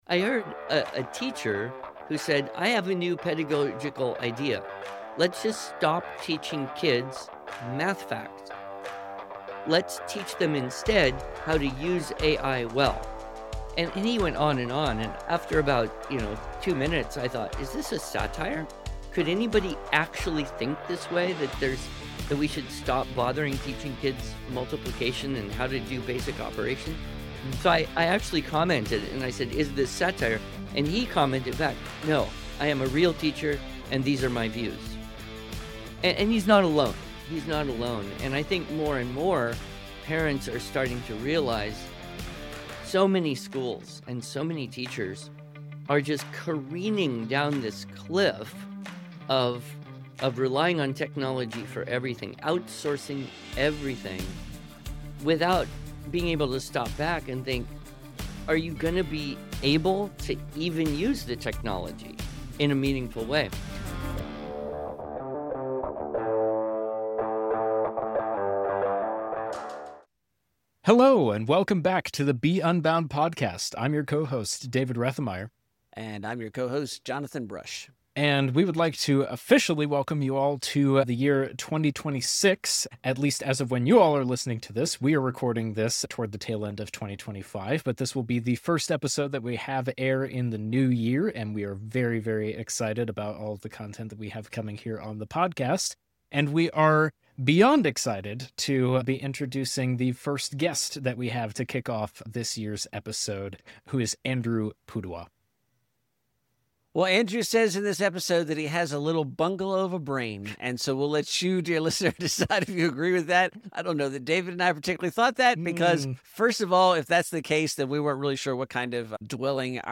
Welcome to 2026! This episode is special for us—not just because it’s our first episode of the new year, but because we get to kick things off with a conversation we’ve been looking forward to for a long time.